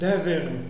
Aqui voce pode ouvir palavras em hebraico.